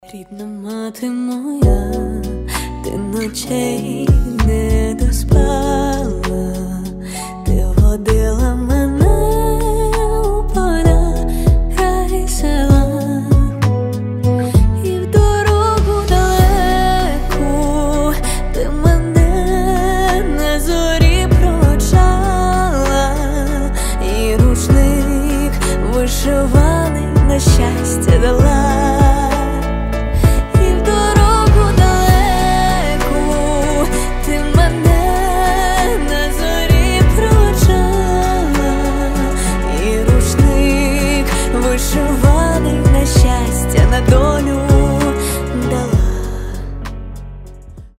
красивые
душевные
Cover